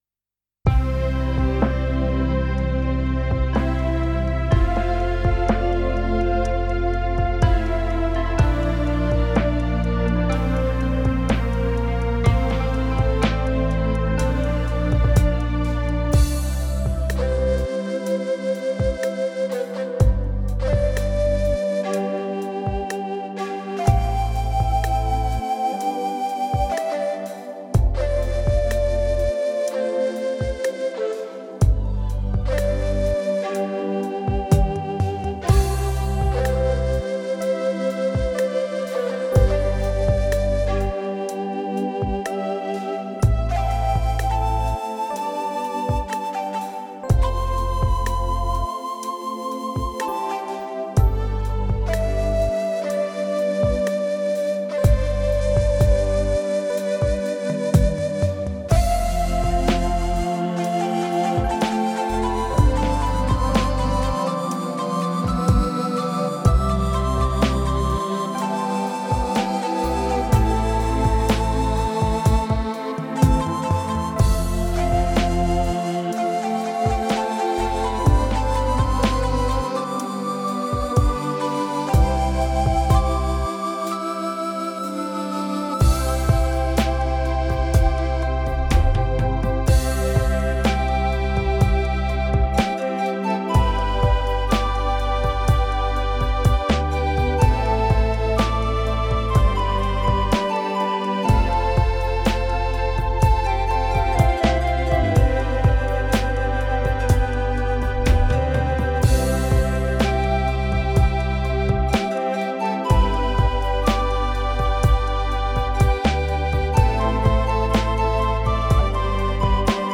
Alors exécuté avec le PSR S770 style Chilout Lounge Tempo : 65  mixing
Mélodie et orchestration voix : Shakuhachi - Nay - BalladPanFlute&Cello - AltoFlute&Contrebass - Diva -
Multipiste, mixing effets, etc...........